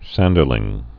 (săndər-lĭng)